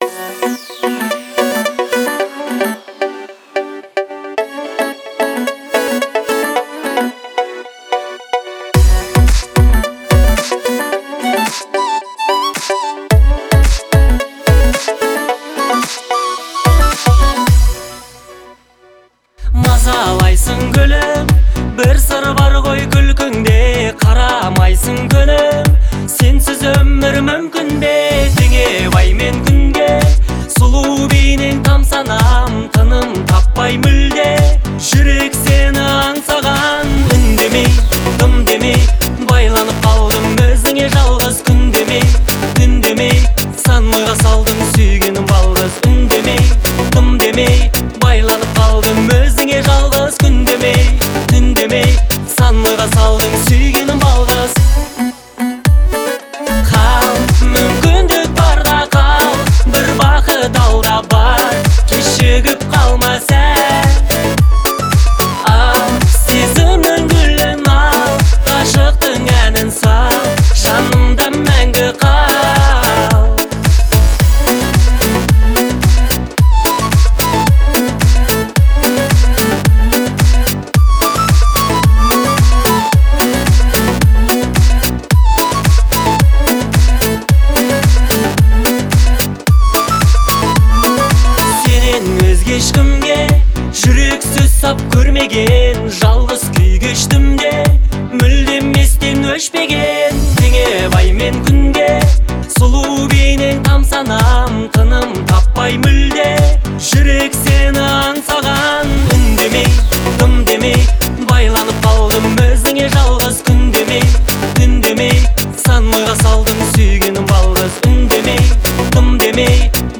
это трек в жанре поп с элементами казахской народной музыки